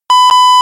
beep_warning.789de308.mp3